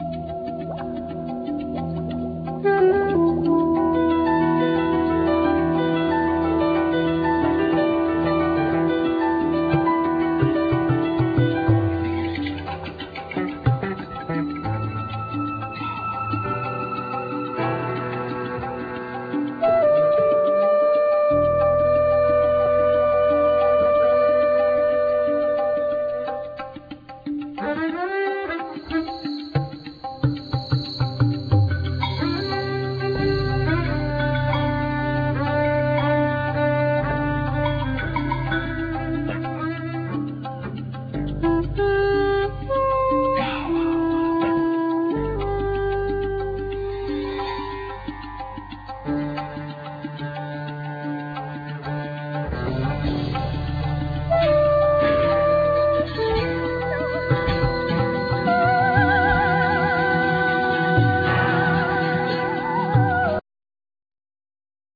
Drums,Gongs
Tabla,Percussions,Voice
Acoustic Bass
Saxophones,Flute
Electric Bass
Keyboards,Piano
Guitar